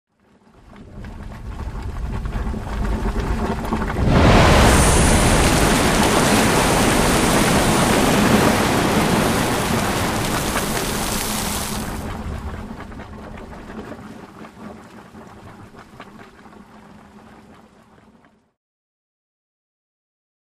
Geyser Boiling And Erupting, Then Bubbling Again